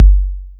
Kick808.wav